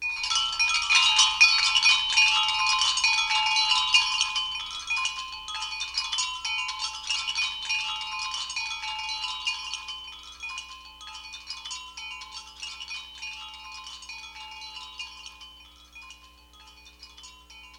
windchim.mp3